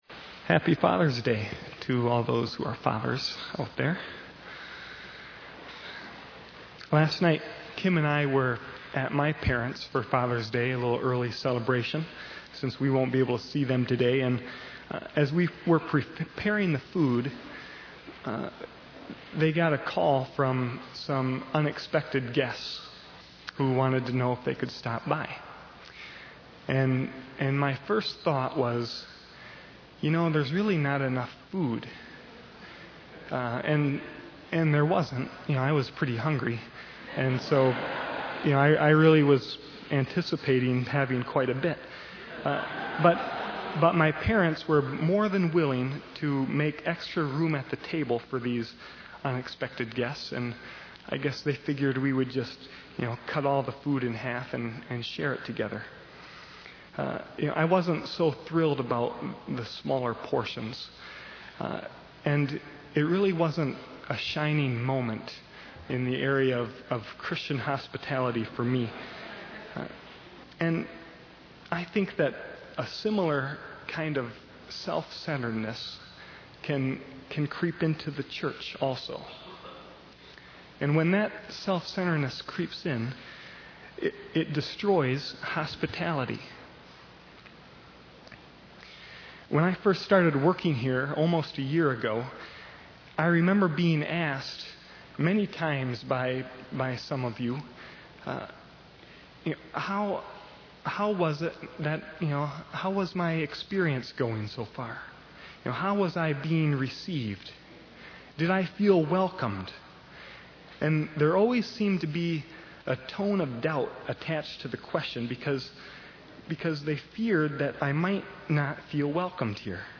Sermon Recordings: 2006 - Church of the Servant
We also have sermons from other years.
Furthermore, since September of this year, thanks to the support of a few members of COS who are funding this project, we are able to digitize approximately a dozen sermons a week from Church of the Servant’s cassette archive.